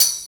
12 TAMB   -L.wav